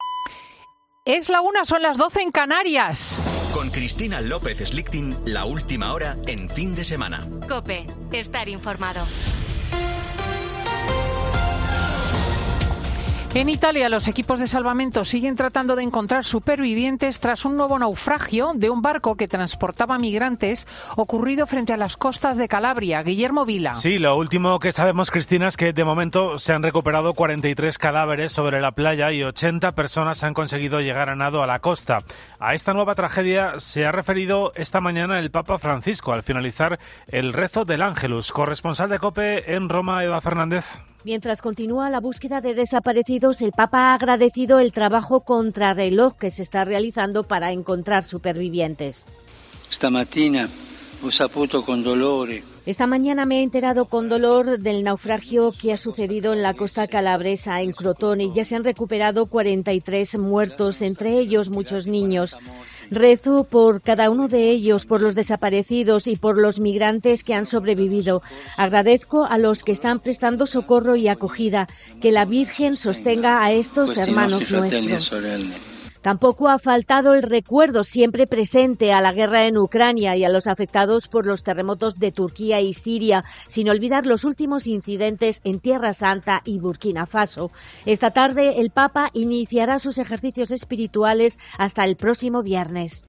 En el ángelus de este domingo, primero de Cuaresma, el Papa ha rezado también por el pueblo ucraniano y ha pedido el cese de la violencia en Tierra Santa y en Burkina Faso